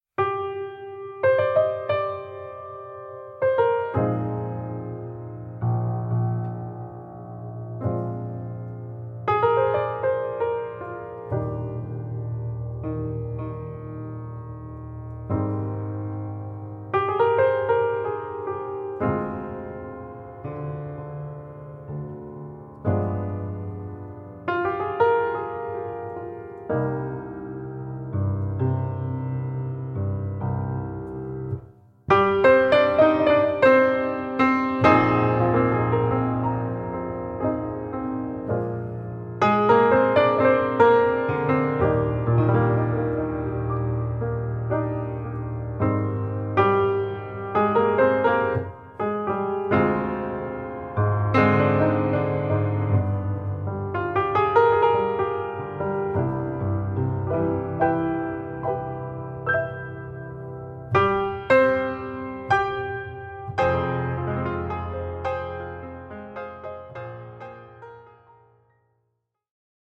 Piano Solo Arrangement